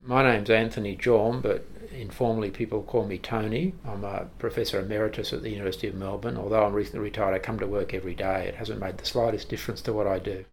excerpt from our conversation